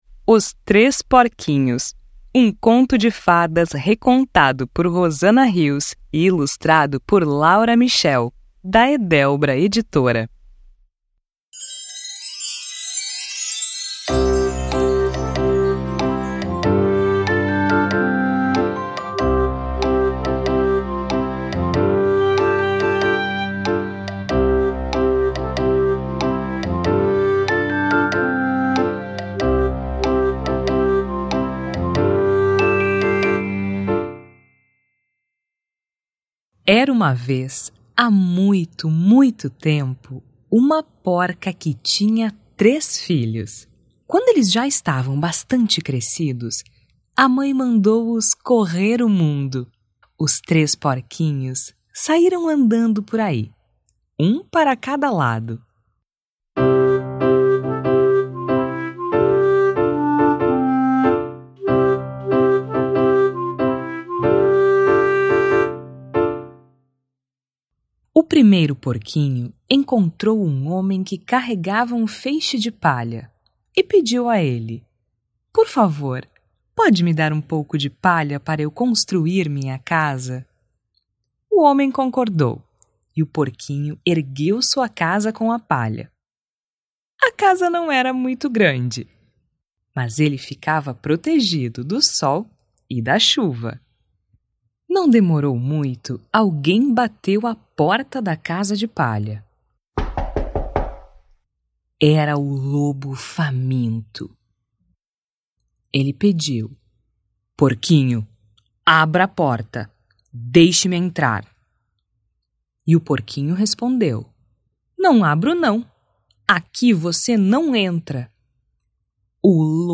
Amostra do audiolivro